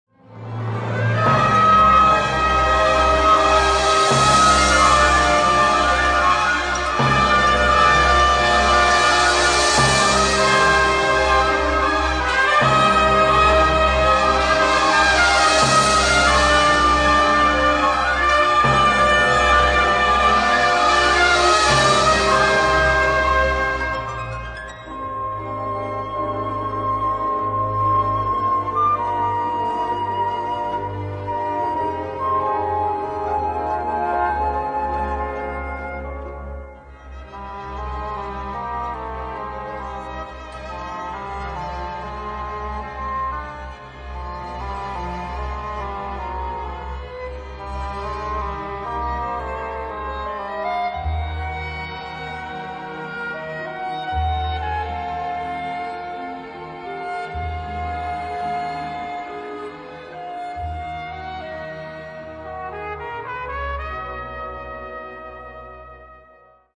for Symphonic Orchestra